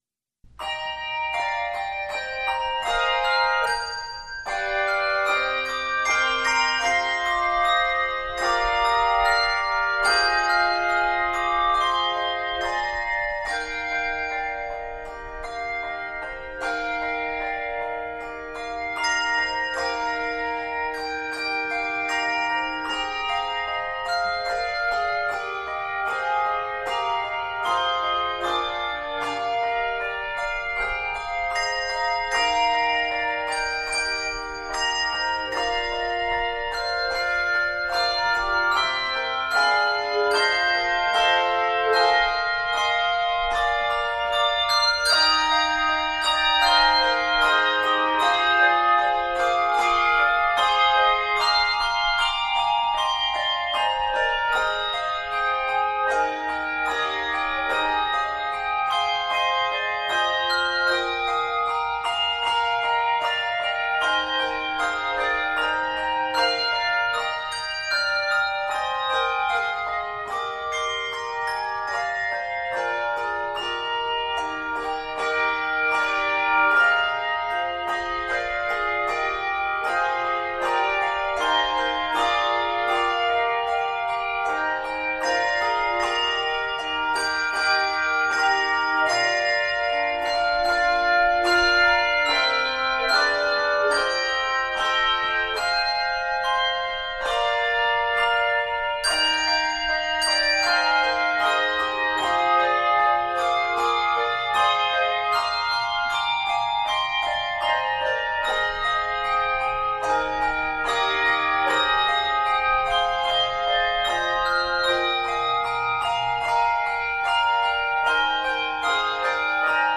two- and three-octave handbell arrangements